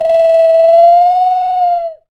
Index of /90_sSampleCDs/NorthStar - Global Instruments VOL-2/PRC_JungleSounds/PRC_JungleSounds